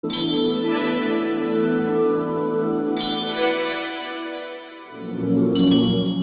Kategorien SMS Töne